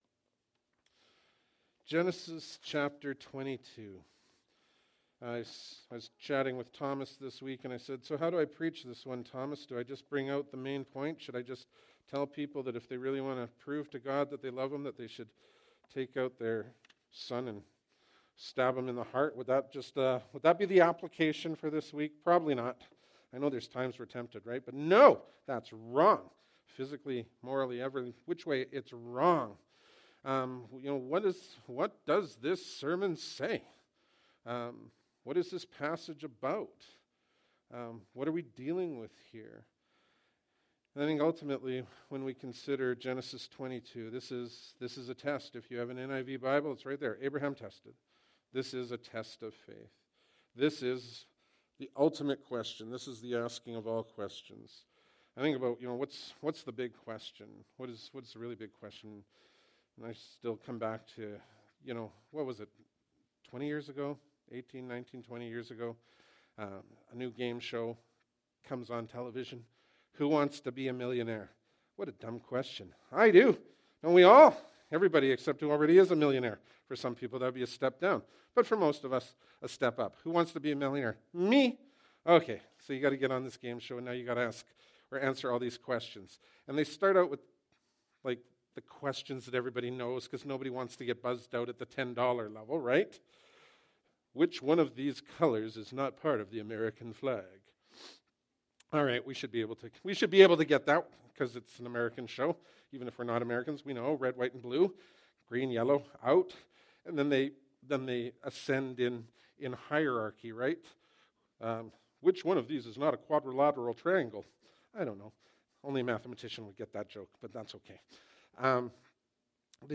Bible Text: Genesis 22:1-19 | Preacher